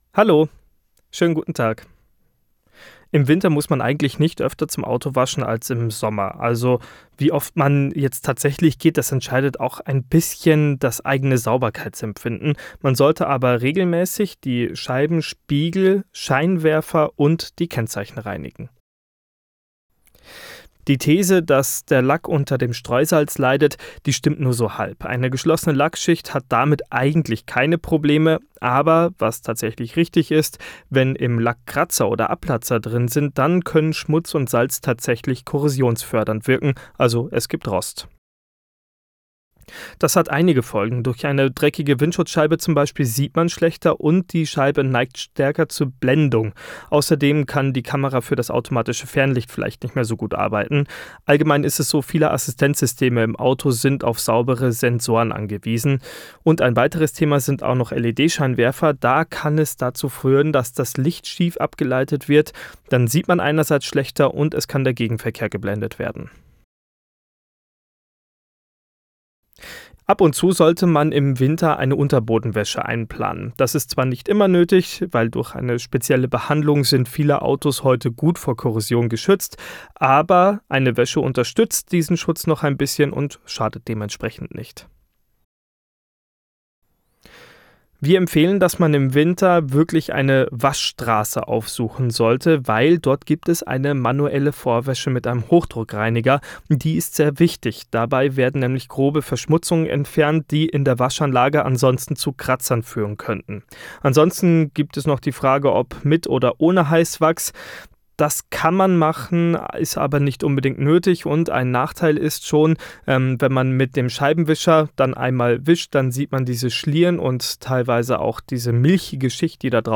adac_autowaesche_winter.mp3